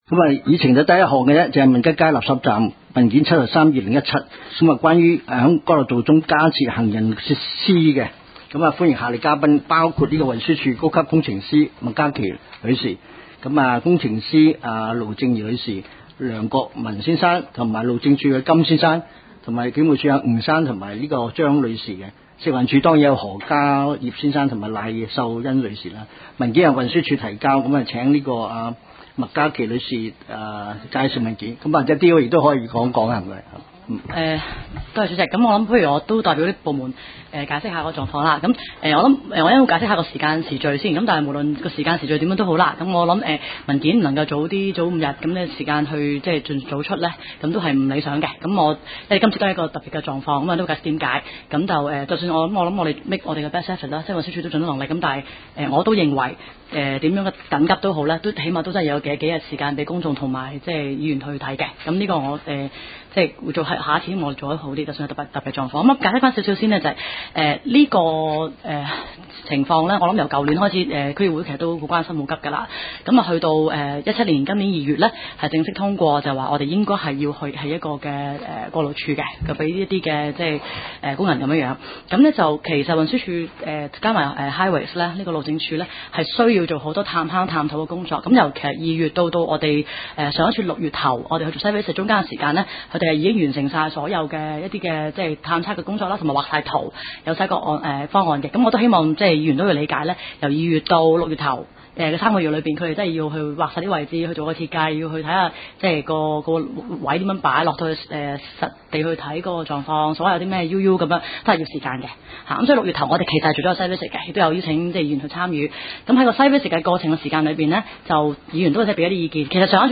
委员会会议的录音记录
地点: 香港中环统一码头道38号 海港政府大楼14楼 中西区区议会会议室